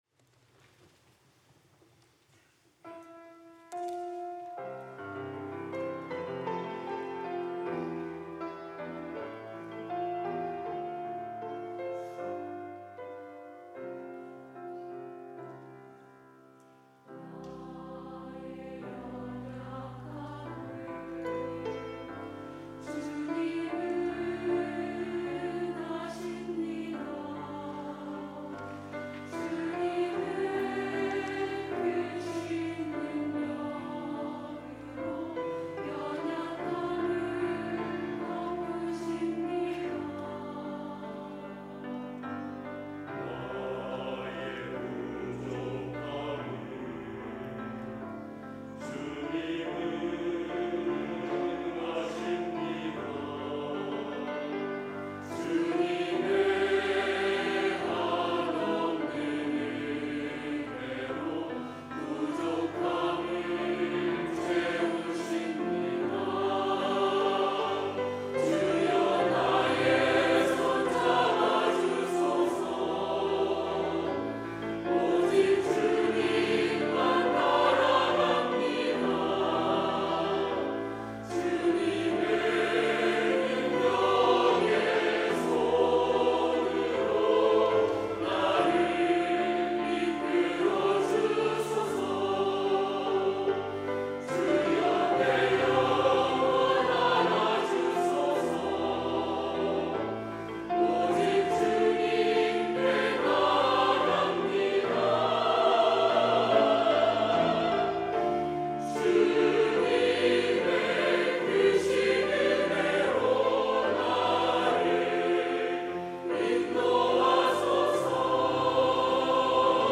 할렐루야(주일2부) - 주님은 아십니다
찬양대